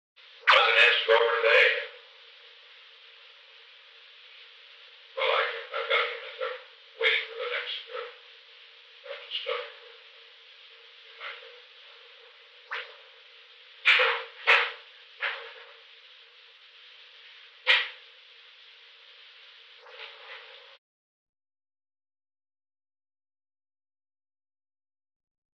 Recording Device: Old Executive Office Building
On March 1, 1973, President Richard M. Nixon and H. R. ("Bob") Haldeman met in the President's office in the Old Executive Office Building at an unknown time between 4:50 pm and 5:40 pm. The Old Executive Office Building taping system captured this recording, which is known as Conversation 416-015 of the White House Tapes.
The President talked with H. R. (“Bob”) Haldeman [?].